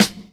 Boom-Bap Snare 97.wav